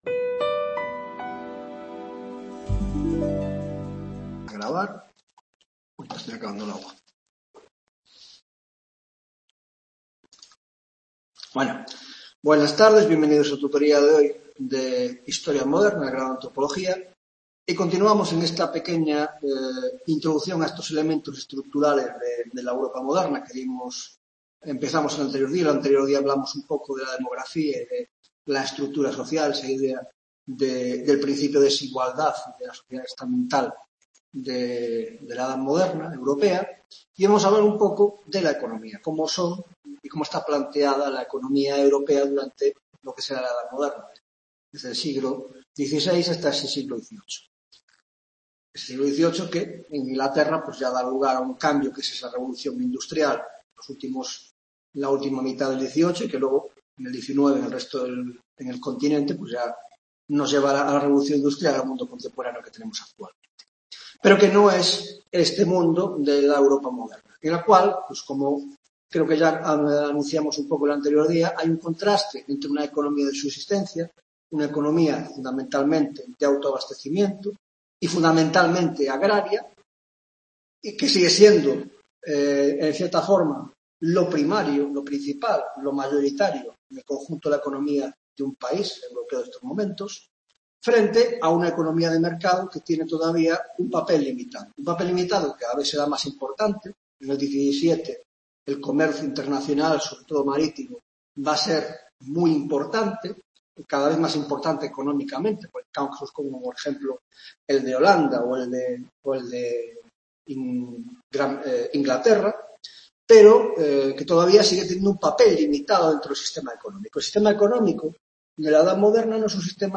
6º Tutoría Historia Moderna (Grado de Antropología Social y Cultural): Elementos estructurales (2ª parte): 1) Economía: 1.1) Introducción: Entre la economía de subsistencia y la de mercado